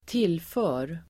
Ladda ner uttalet
Uttal: [²t'il:fö:r]